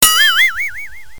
SFX叮 晕音效下载
SFX音效